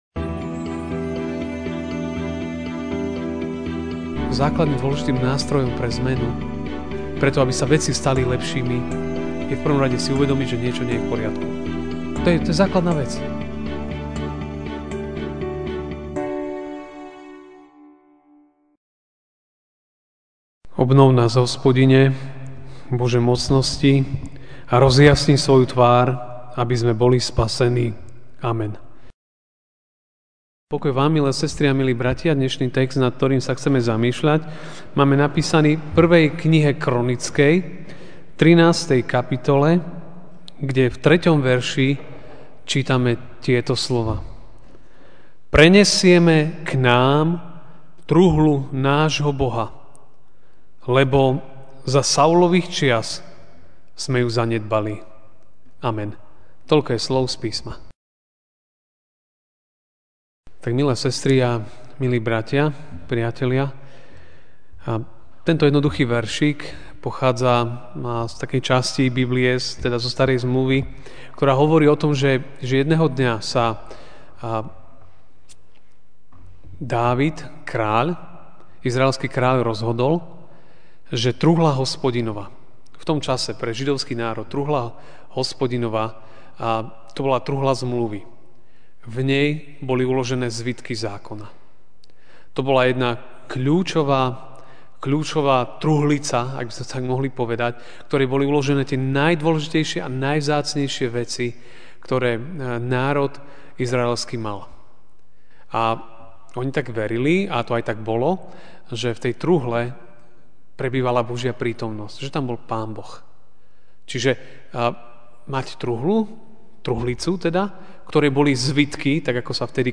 máj 21, 2017 Niečo nie je v poriadku MP3 SUBSCRIBE on iTunes(Podcast) Notes Sermons in this Series Večerná kázeň: Niečo nie je v poriadku (1. Kron. 13, 3) Prenesieme k nám truhlu nášho Boha, lebo za Saulových čias sme ju zanedbali.